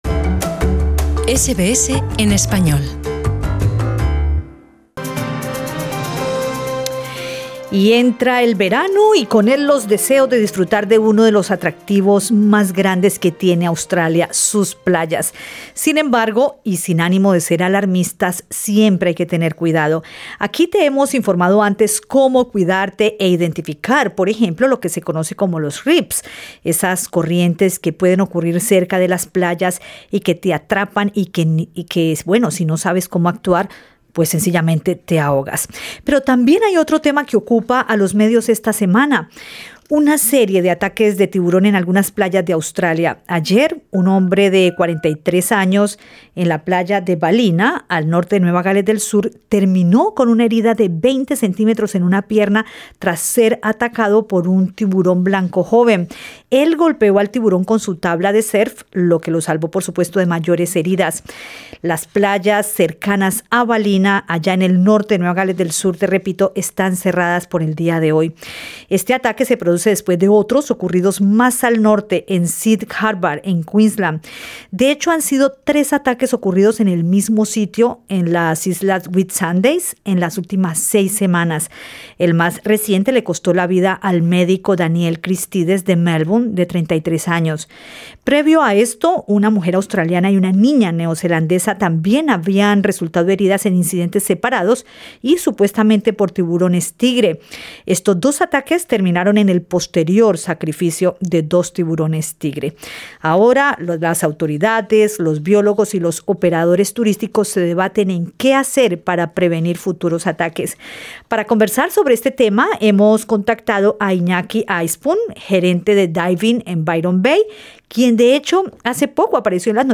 En entrevista con Radio SBS